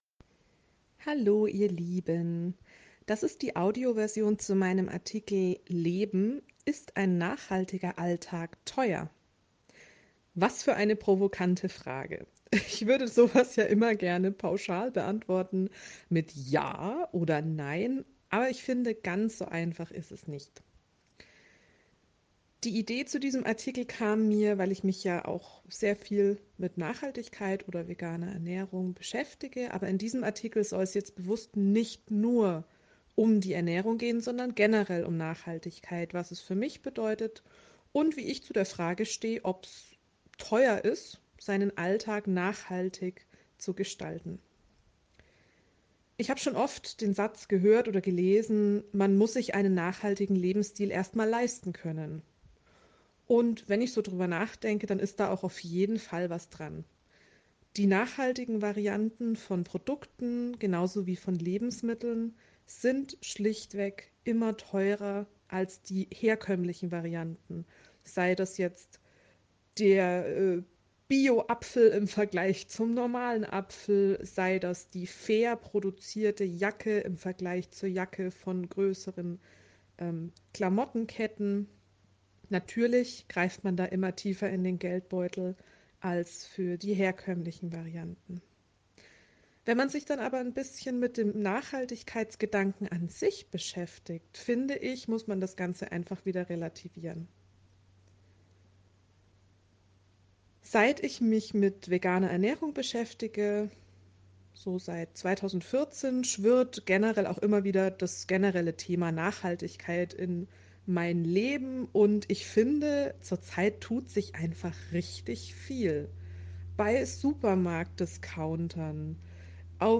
Dann höre Dir doch einfach diesen Artikel an – in der Audio-Version, mit zusätzlichen Anekdoten, Infos und Tipps.